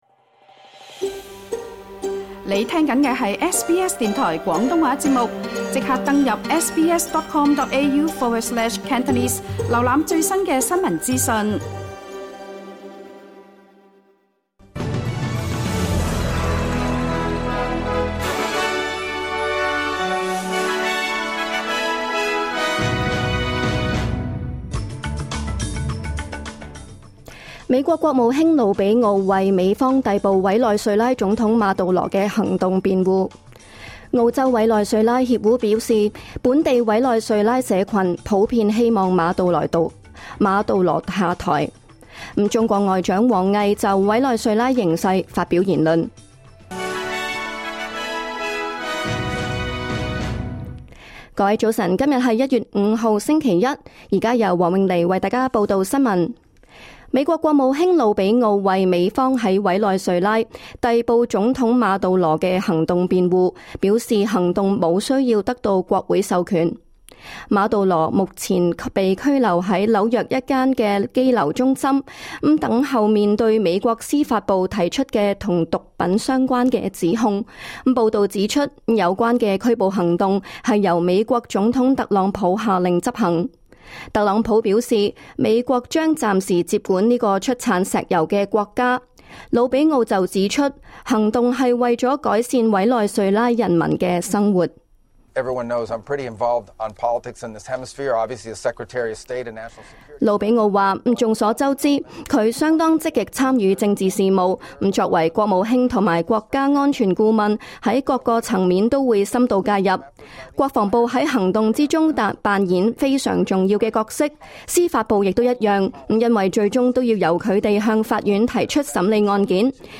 2026 年 1 月 5 日 SBS 廣東話節目九點半新聞報道。